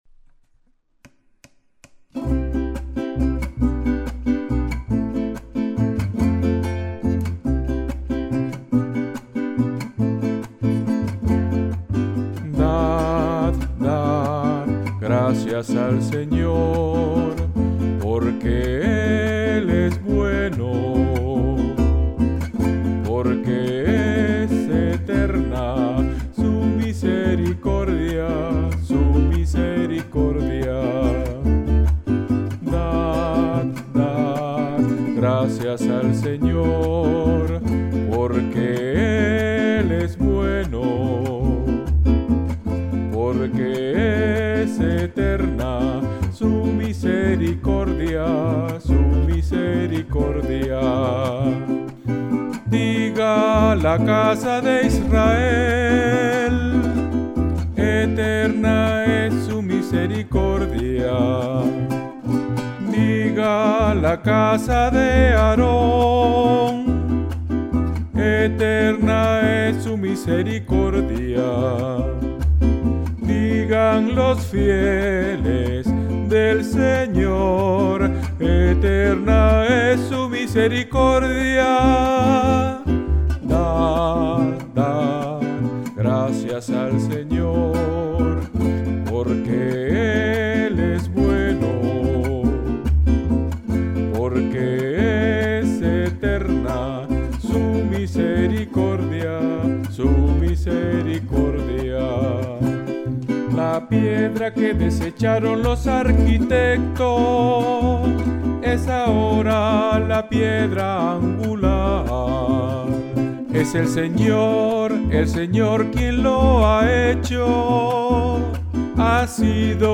Música para la Misa de la Divina Misericordia
Partes: Voz principal y acordes Sono estudio
Voz principal